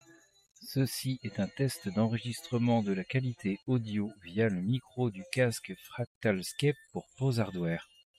Fractal Scape có tính năng ENC (Environmental Noise Cancellation) giúp giảm tiếng ồn xung quanh. Sự khác biệt có thể nhận thấy, dù là nhỏ.
• [Mẫu âm thanh Micro rời, nhạc lớn và bật ENC]